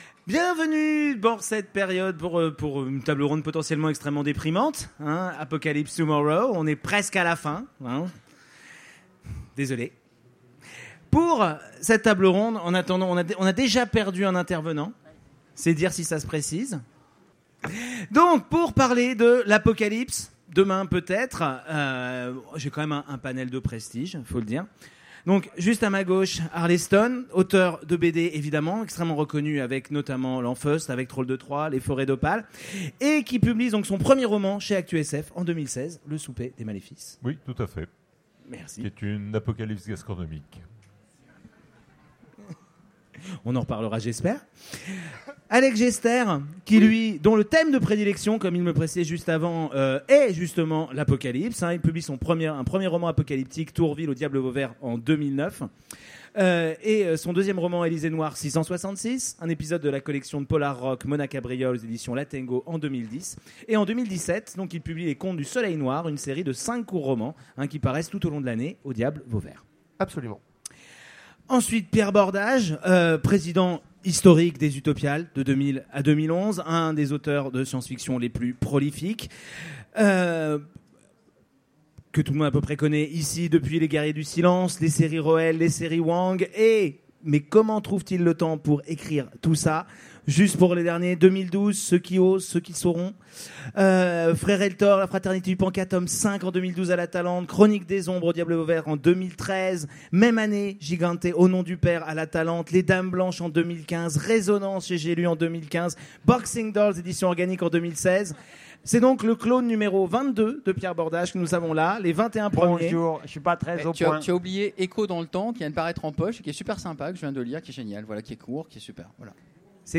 Utopiales 2017 : Conférence Apocalypse tomorrow